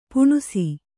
♪ puṇusi